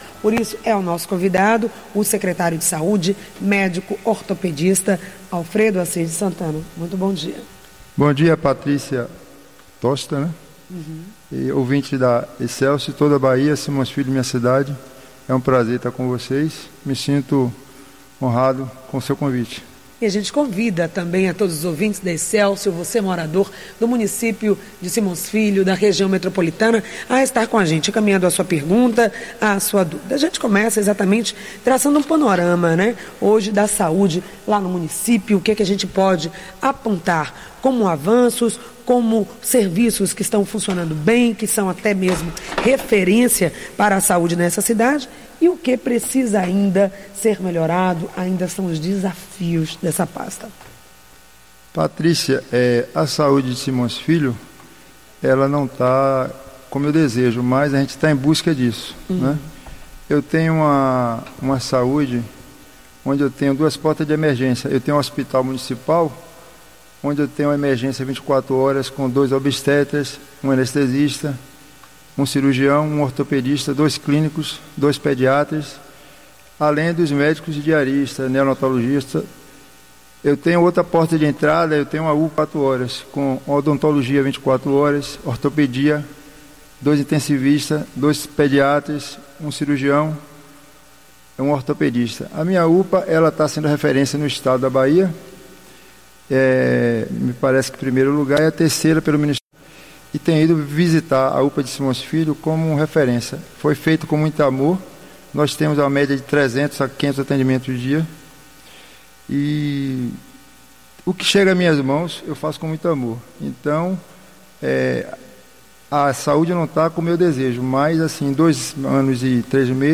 Entrevista com o Secretário Municipal de Saúde de Simões Filho -